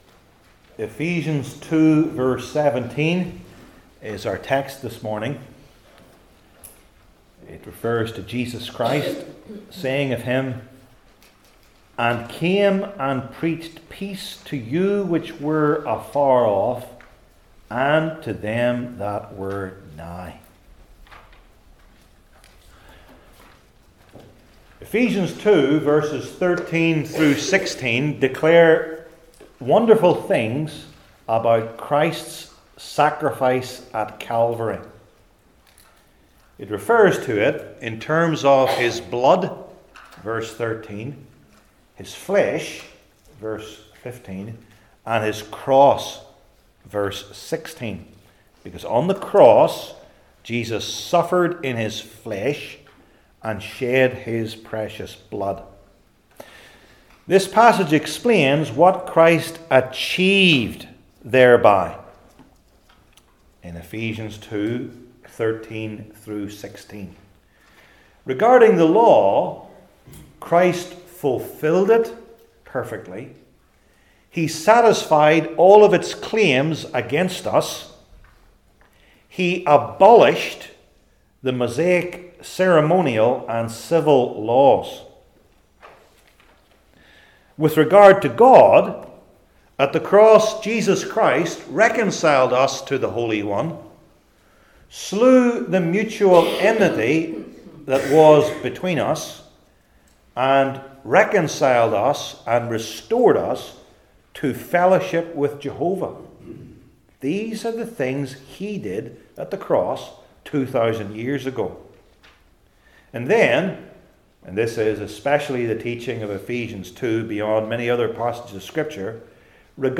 New Testament Sermon Series I. The Preaching II.